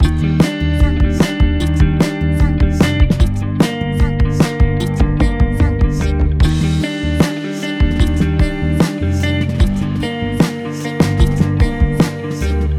2. ハーフタイム
インディーロック 急ぎめ→チルめ
r1-time-normal-to-half-rock.mp3